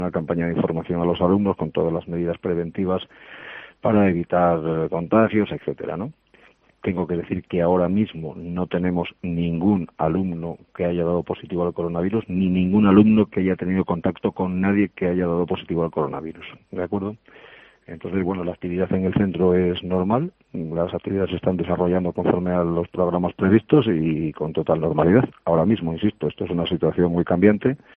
El Director Escuela Nacional de Policía, José Luis Tejedor, explica el Protocolo Coronavirus de la Escuela Nacional de Policia en COPE Ávila